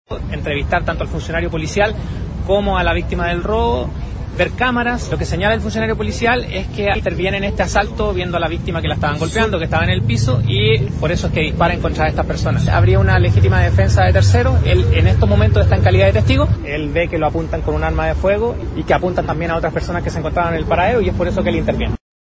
El fiscal Felipe Olivari indicó que dispuso de múltiples diligencias lideradas por la PDI para esclarecer las circunstancias del incidente. Agregó que, por el momento, todo apunta a que el carabinero actuó en legítima defensa de terceras personas.